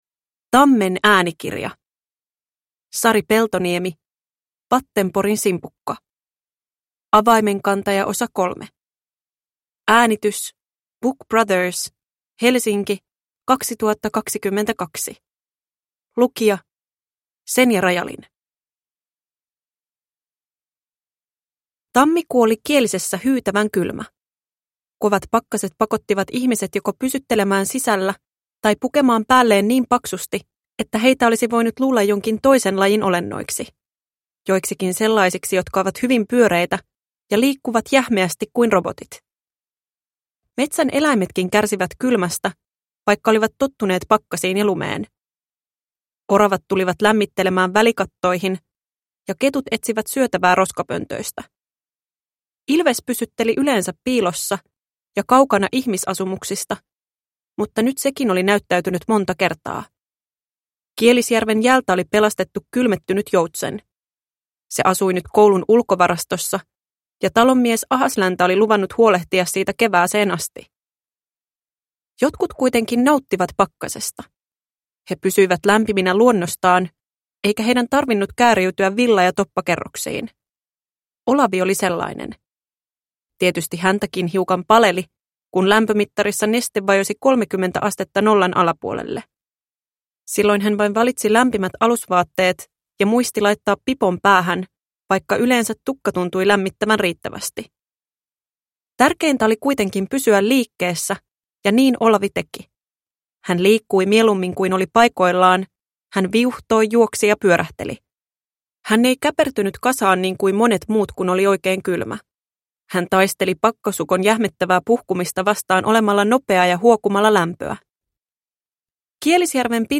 Vattenporin simpukka – Ljudbok – Laddas ner